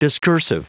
Prononciation du mot discursive en anglais (fichier audio)
Prononciation du mot : discursive